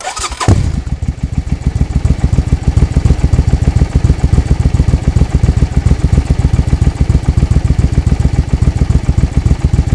Index of /90_sSampleCDs/AKAI S6000 CD-ROM - Volume 6/Transportation/MOTORCYCLE
1200-START.WAV